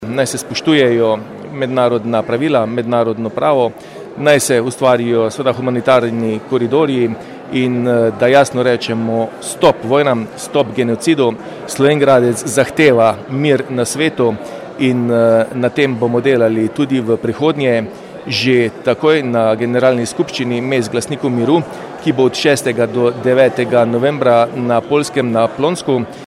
Da naj govori diplomacija, ne orožje, je poudaril slovenjgraški župan Tilen Klugler.
IZJAVA TILEN KLUGLER _1.MP3